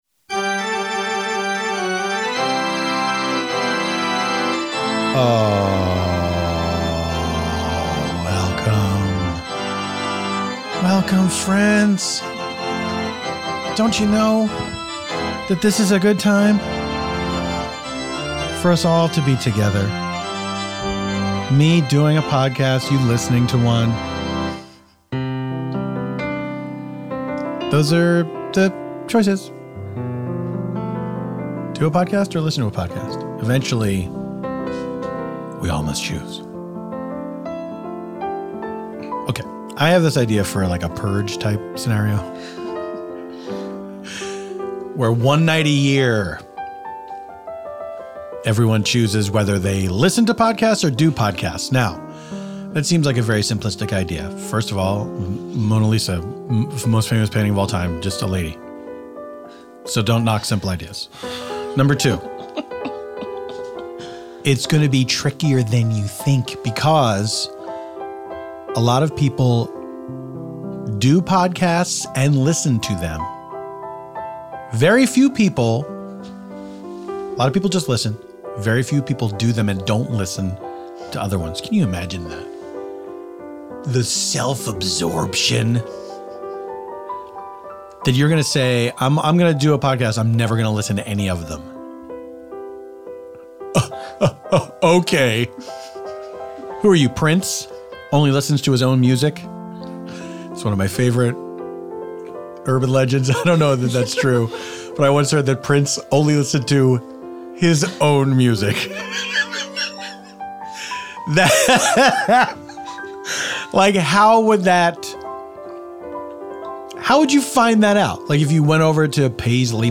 This time out, Paul's special guest is actress Vella Lovell of Crazy Ex-Girlfriend! Vella chats about which celebrity she would like to die in the arms of, the movie Hook, and being a kid watching TV trying to not get caught watching something she shouldn't have.